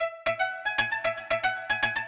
115 BPM Beat Loops Download